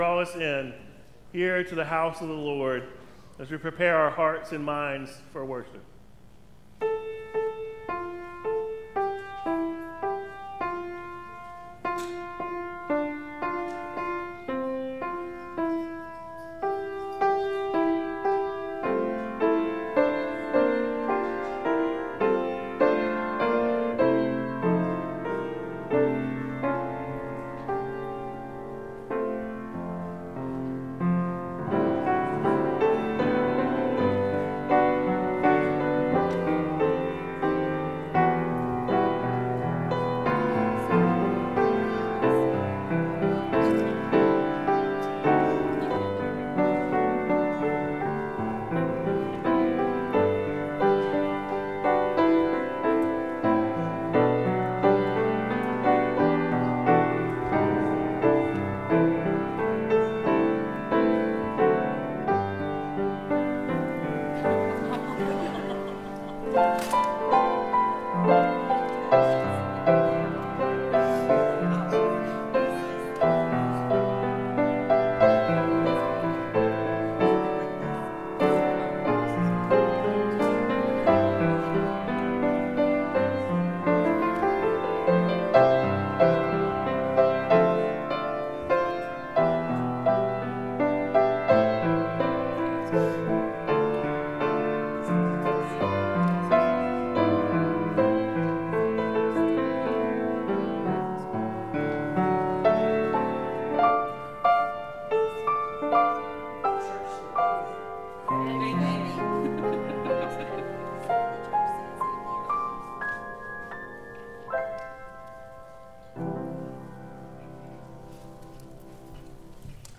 Acts 11:19-30 Service Type: Morning Bible Text